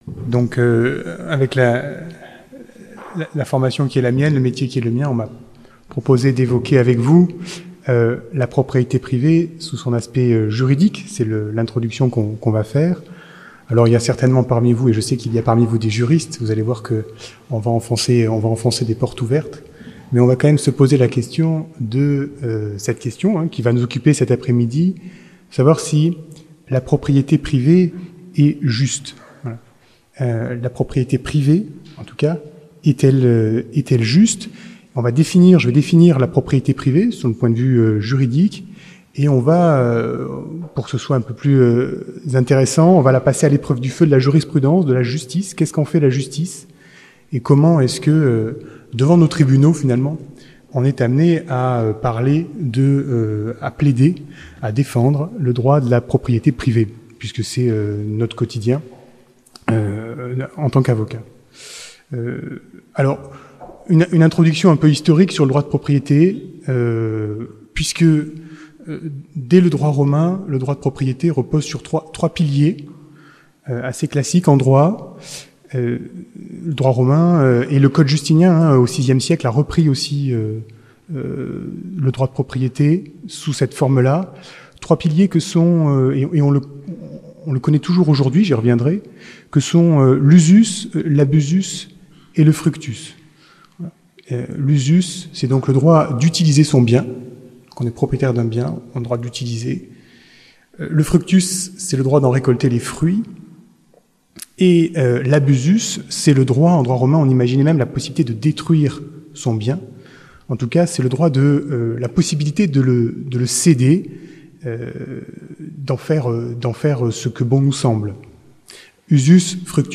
Ste Baume.